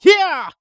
CK发力01.wav
CK发力01.wav 0:00.00 0:00.62 CK发力01.wav WAV · 53 KB · 單聲道 (1ch) 下载文件 本站所有音效均采用 CC0 授权 ，可免费用于商业与个人项目，无需署名。
人声采集素材/男2刺客型/CK发力01.wav